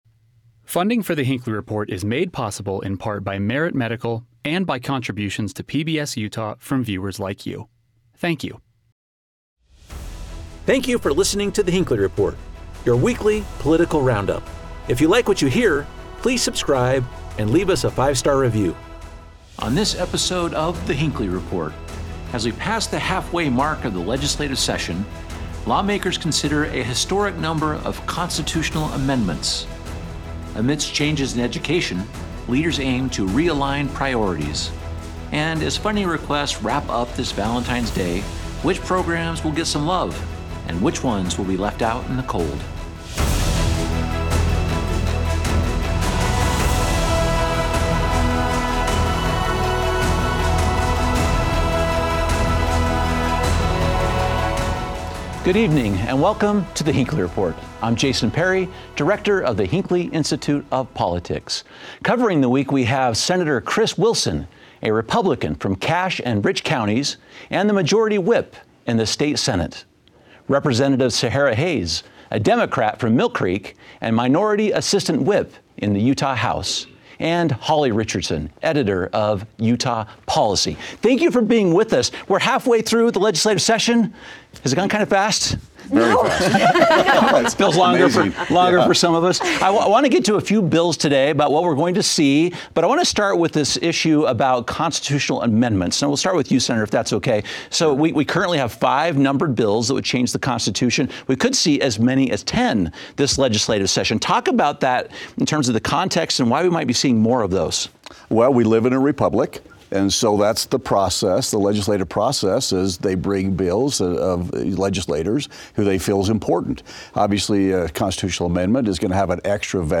Our expert panel examines the proposals and discusses why we are seeing so many.